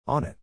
発音：/ˈɑn ɪt/